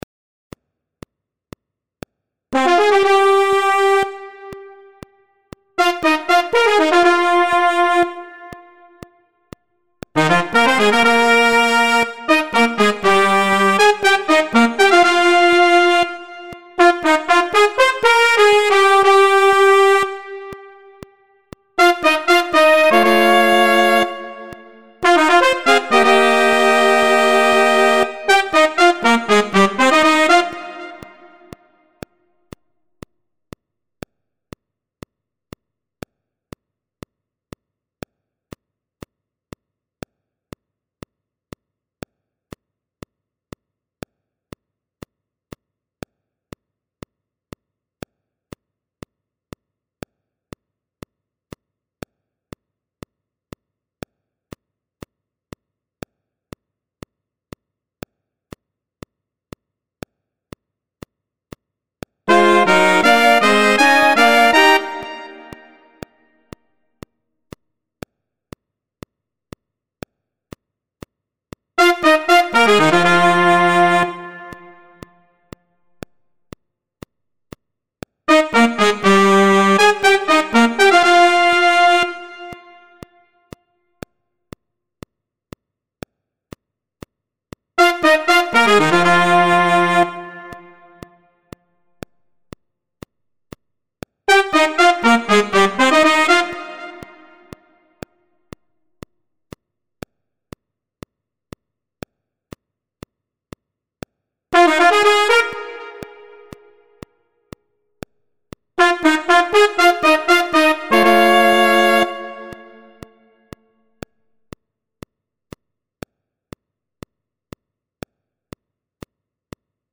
TOM ORIGINAL.
Partitura do Naipe de Metais para os seguintes instrumentos:
1. Sax Tenor;
2. Sax Alto;
3. Trompete; e,
4. Trombone.